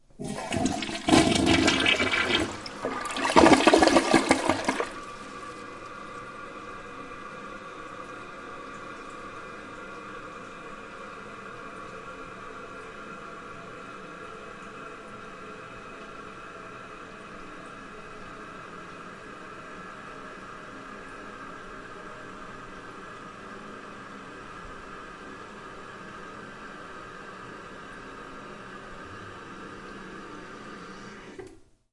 卫生间的水 " 冲水马桶02
Tag: 小便 冲洗 冲洗 厕所 船尾 WC 漏极 浴室 厕所 盥洗室